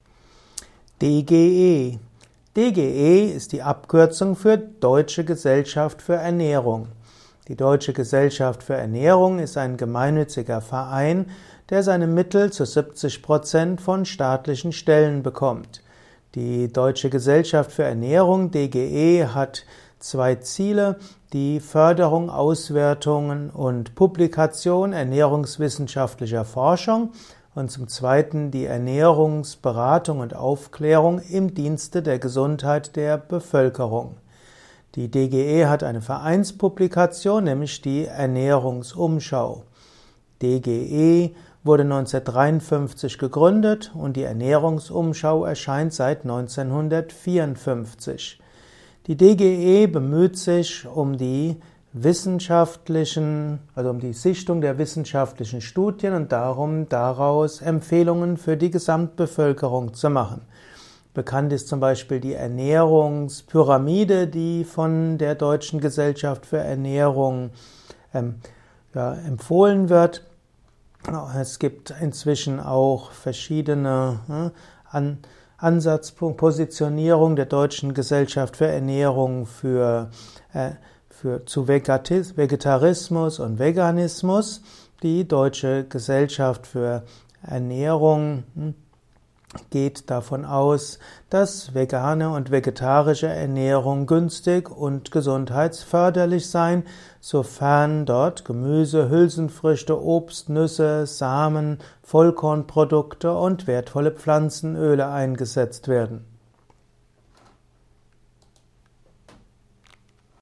Ein Kurzvortrag über DGE - die Abkürzung der Deutschen Gesellschaft für Ernährung